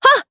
Lorraine-Vox_Attack2_jp.wav